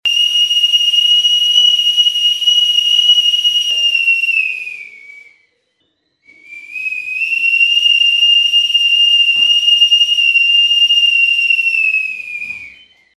Набросок с живым басом (minimal/tech)
Прошу оценить набросок с моей новенькой бас гитаркой.